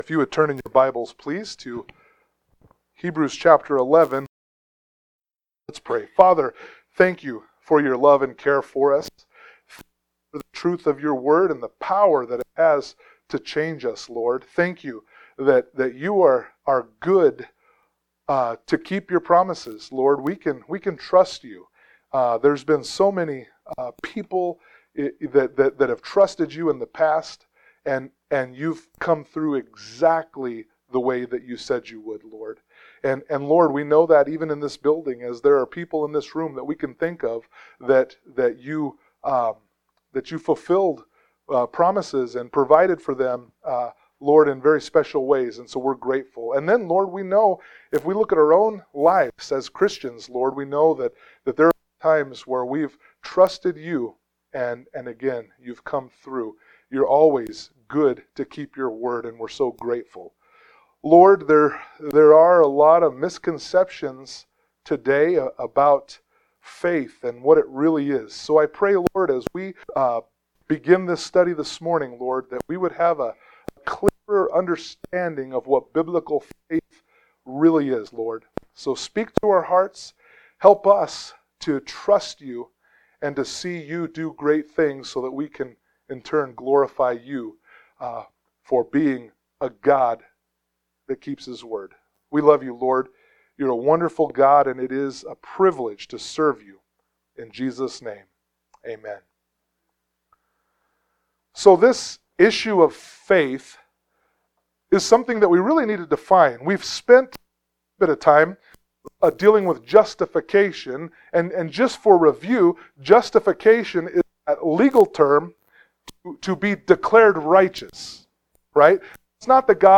Please note: Due to some technical difficulties and special formatting of this service, portions of the audio have been cut and edited for clarity in an audio format.
Service Type: Sunday Morning Worship